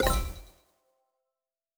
Special & Powerup (38).wav